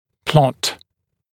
[plɔt][плот]представлять в виде графика, вычерчивать график или диаграмму